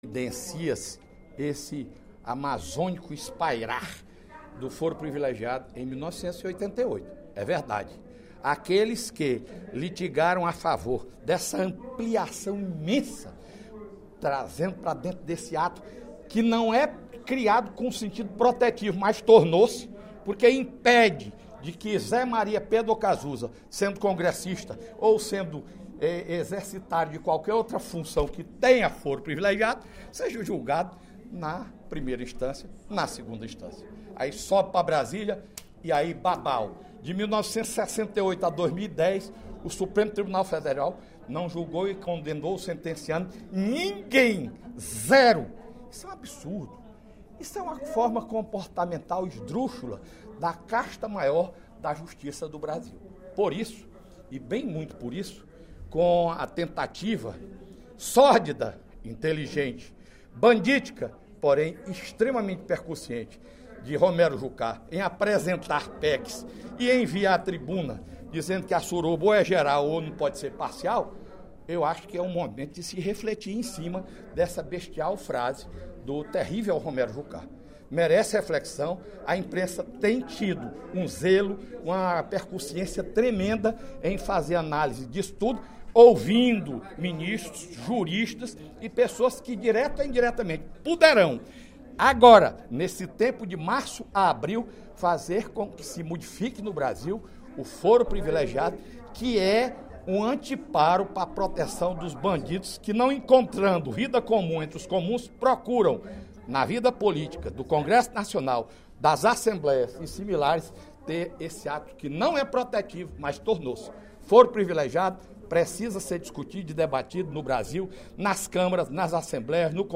O deputado Fernando Hugo (PP) manifestou, em pronunciamento no primeiro expediente da sessão plenária desta quinta-feira (02/03), posição contrária à ampliação do foro privilegiado no Brasil.